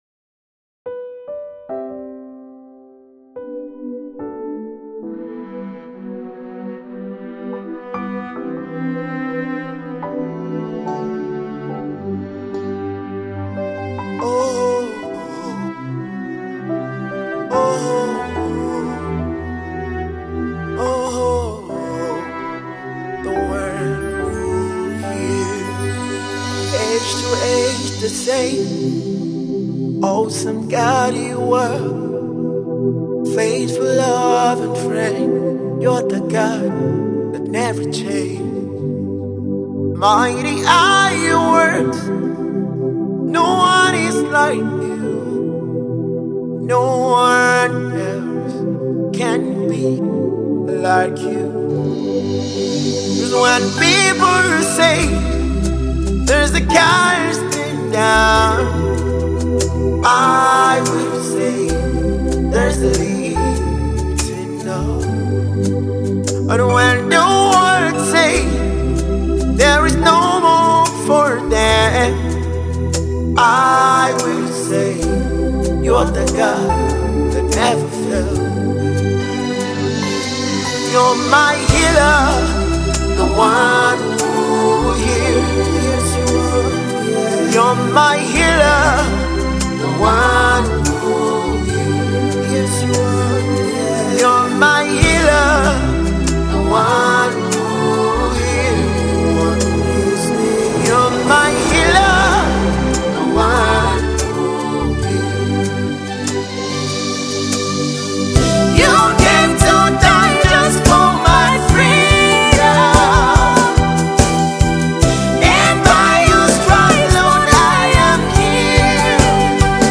Nigerian gospel music minister and prolific songwriter